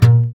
Index of /90_sSampleCDs/Sound & Vision - Gigapack I CD 2 (Roland)/GUI_ACOUST. 32MB/GUI_Acoust. Slap